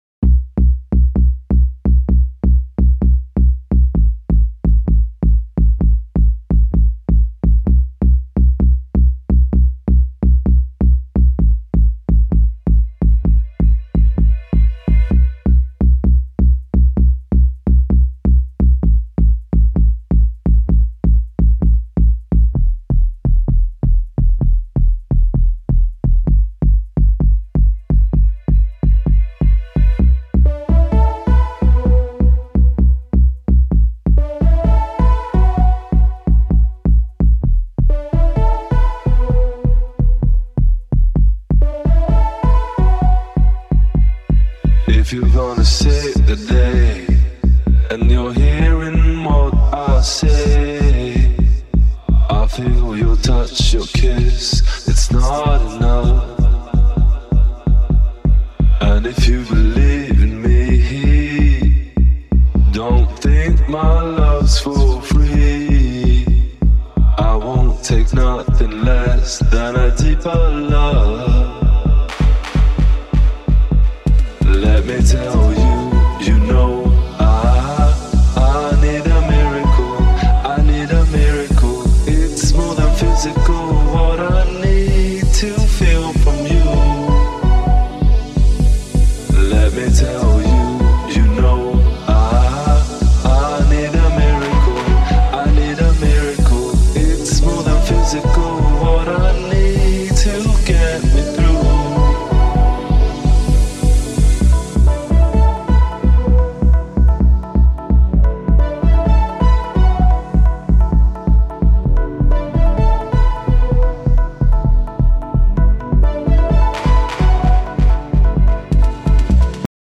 Beatless Version